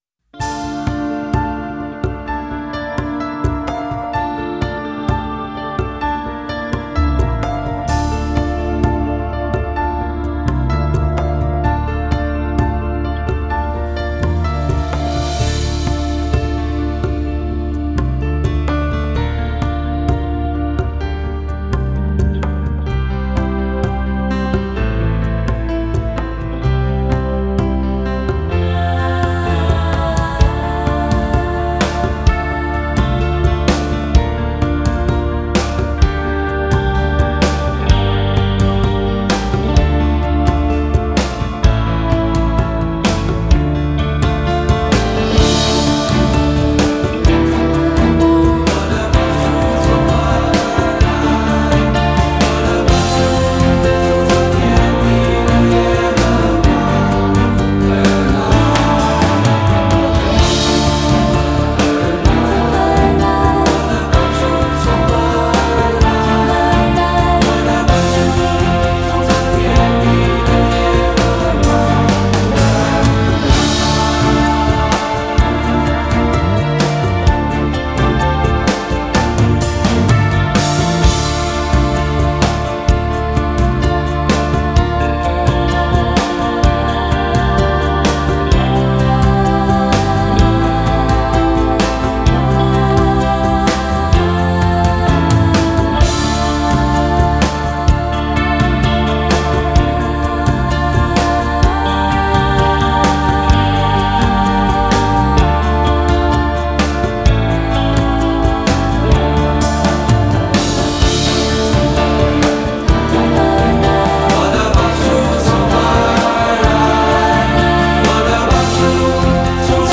mp3 伴唱音樂